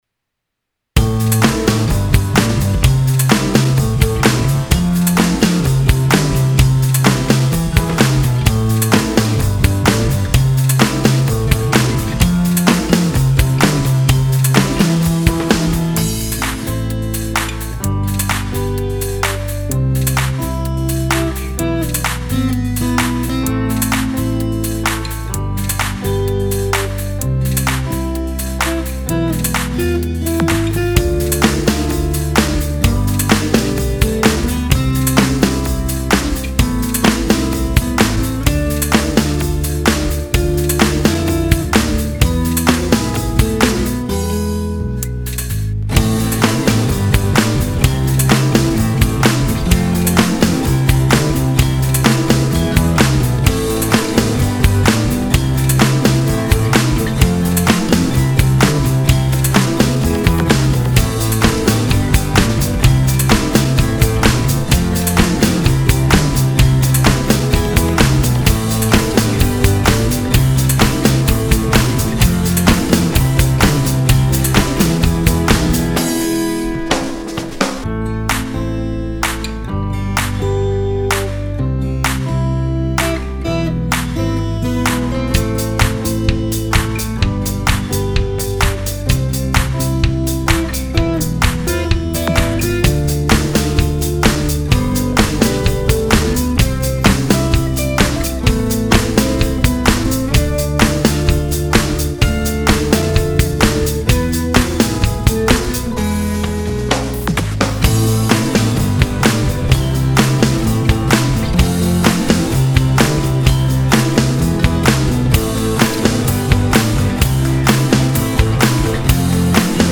A chilly music
:) holiday fun light nice positive plane vacation dance